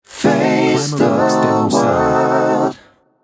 “Face the world” Clamor Sound Effect
Can also be used as a car sound and works as a Tesla LockChime sound for the Boombox.